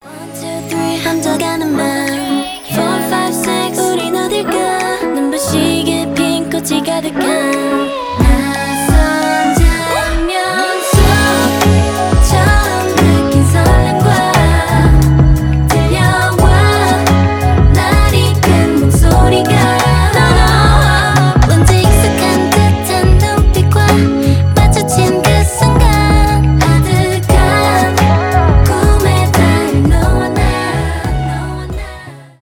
rnb
k-pop